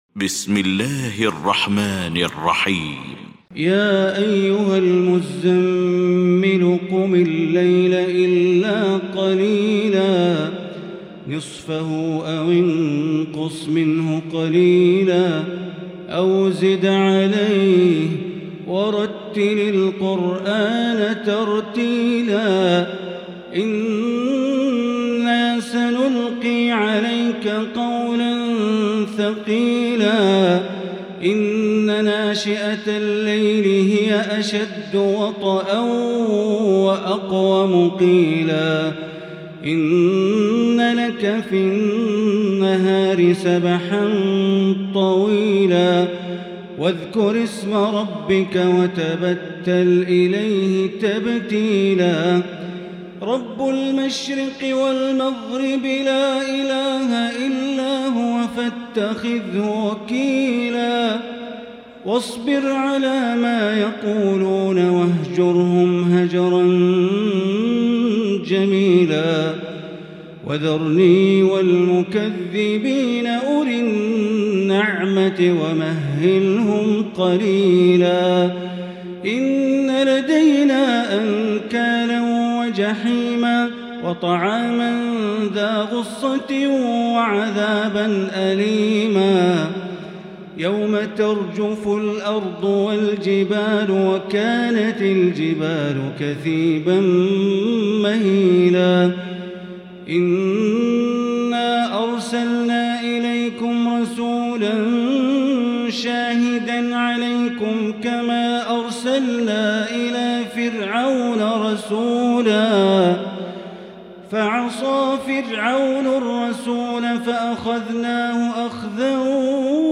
المكان: المسجد الحرام الشيخ: معالي الشيخ أ.د. بندر بليلة معالي الشيخ أ.د. بندر بليلة المزمل